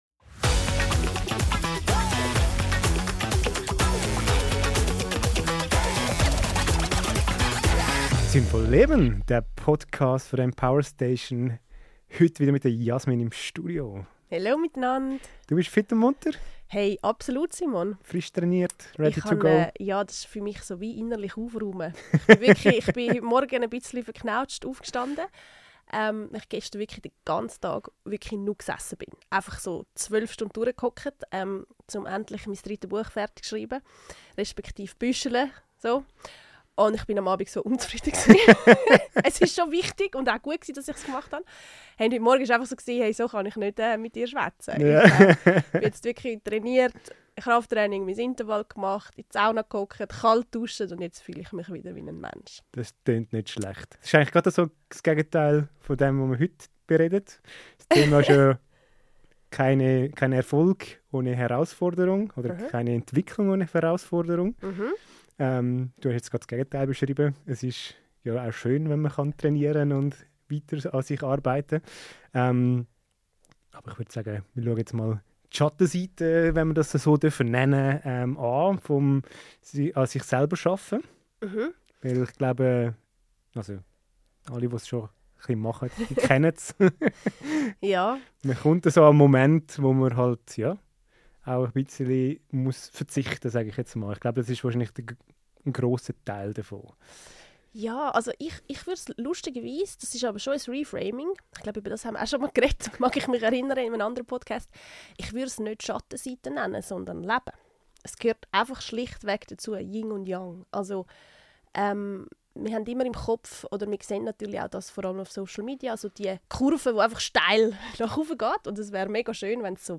Ein Gespräch über Mut, Transformation und den sinnvollen Umgang mit dem, was weh tut – und warum genau dort oft unser größter Schatz liegt.